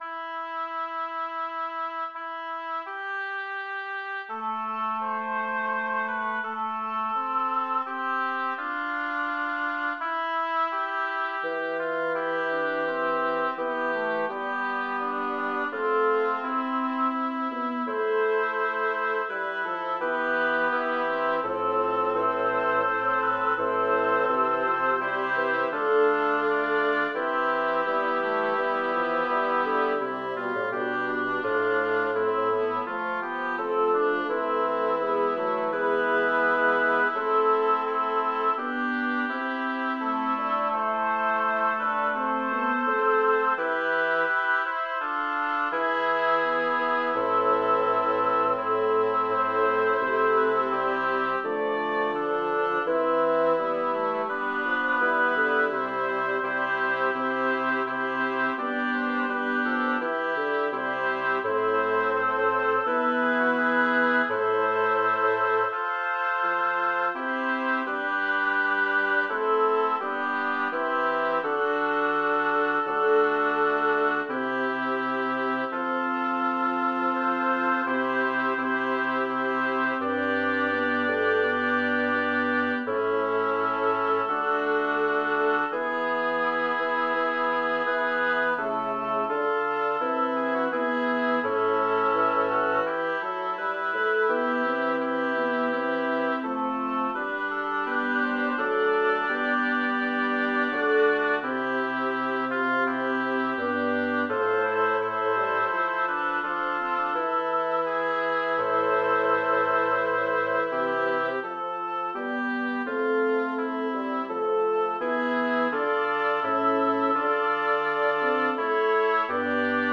Title: Multae tribulationes Composer: Jacob Meiland Lyricist: Number of voices: 5vv Voicing: SSATB Genre: Sacred, Motet
Language: Latin Instruments: A cappella